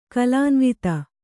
♪ kalānvita